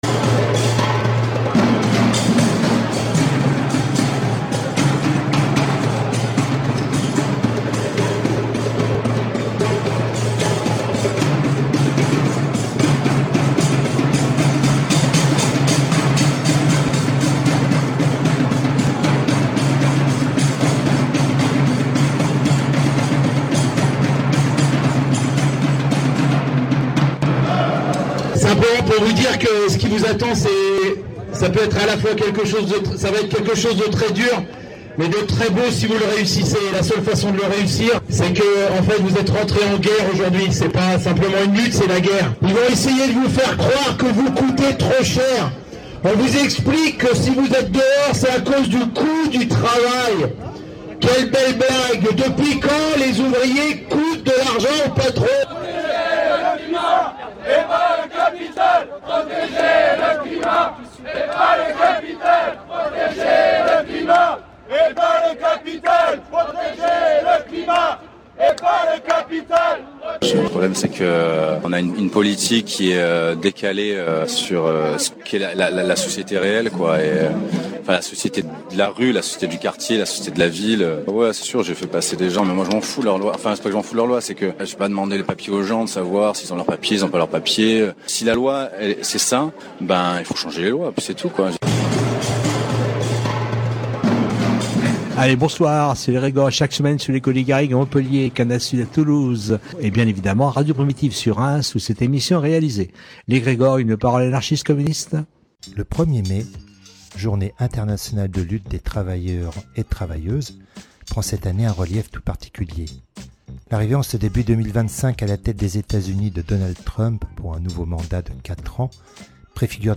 Dans cette manifestation, qui partait de la bourse du travail, pour se rendre vers le quartier de la Maladrerie, nous entendrons plusieurs interventions qui auront une résonance avec le 1er mai et la situation à Aubervilliers. Dans la seconde partie d’émission, nous serons dans la manifestation parisienne du 1er mai, dans laquelle, nous avons rencontré des membres de l’association des travailleurs du Congo-Kinshasa, un membre de l’assemblée de citoyens argentins en France, pour parler de la situation en Argentine, puis des salarié-e-s de Monoprix en bute a la répression syndicale et nous terminerons avec la situation de l’institut mutualiste de Montsouris. classé dans : société Derniers podcasts Découvrez le Conservatoire à rayonnement régional de Reims autrement !